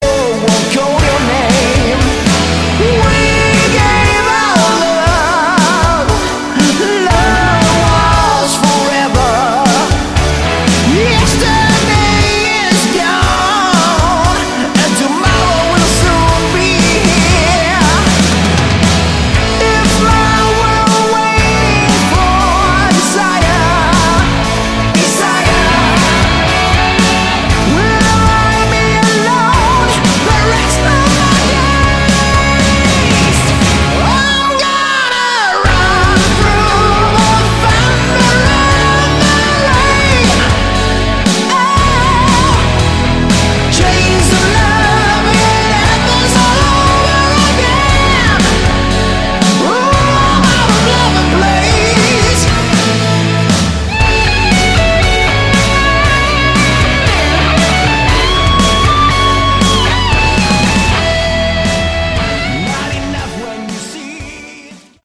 Category: Hard Rock
vocals, backing vocals
guitars, keyboards, backing vocals